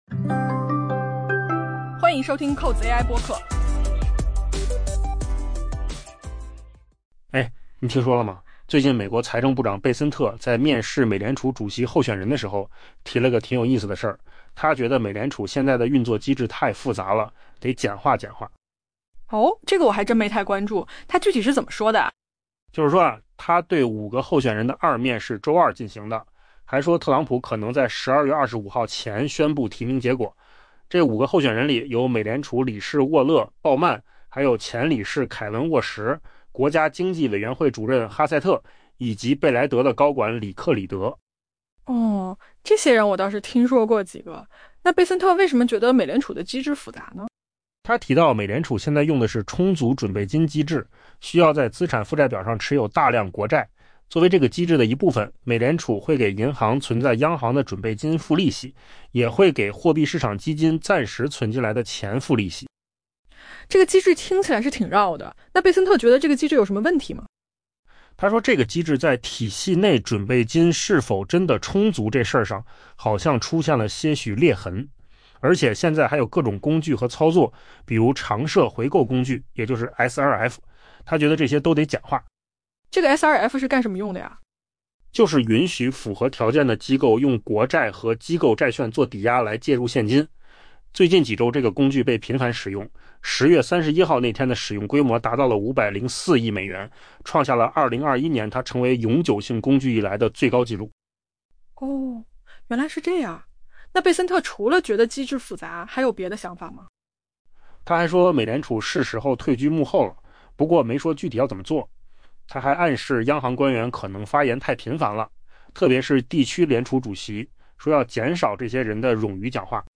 AI 播客：换个方式听新闻 下载 mp3 音频由扣子空间生成 美国财政部长贝森特表示，他为美联储下一任主席职位进行的面试中，核心主题之一是简化这家全球最大的央行——他指出，美联储对货币市场的管理方式已变得过于复杂。